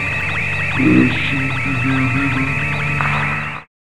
5807R FX-VOX.wav